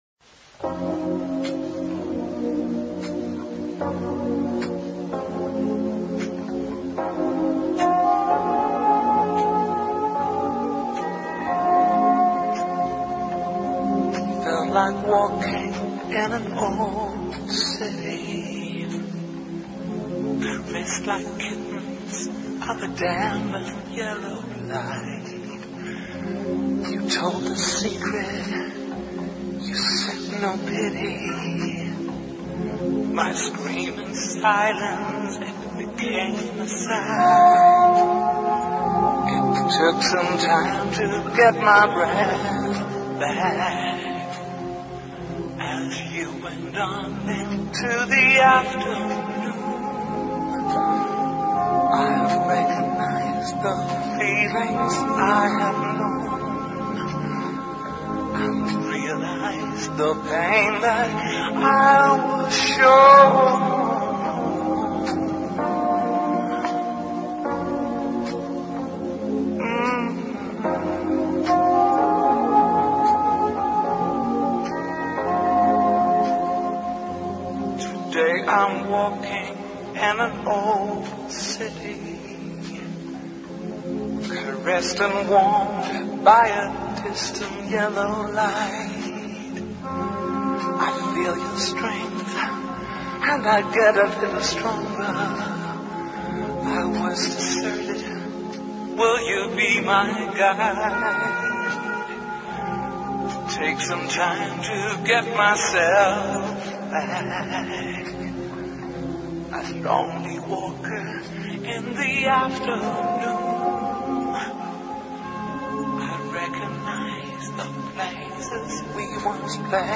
01 - SOUL